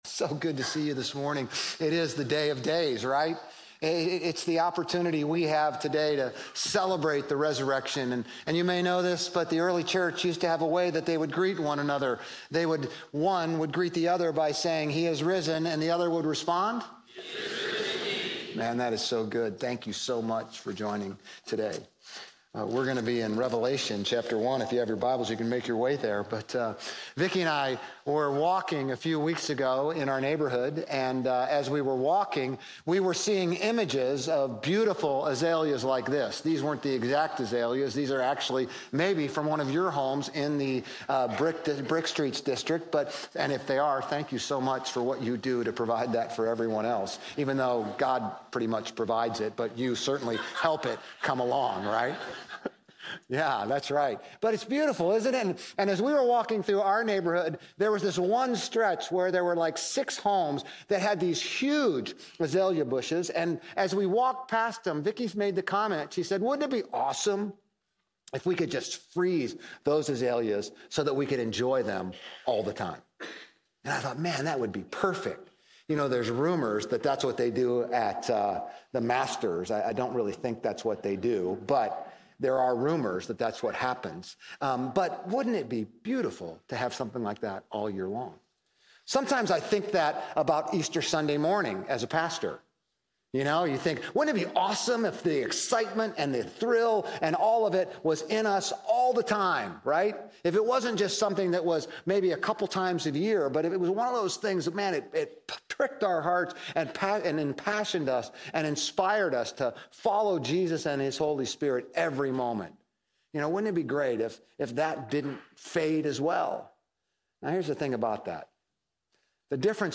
Grace Community Church Old Jacksonville Campus Sermons 4_20 Old Jacksonville Campus Apr 20 2025 | 00:32:31 1x 00:00 / 00:32:31 Subscribe Share RSS Feed Share Link Embed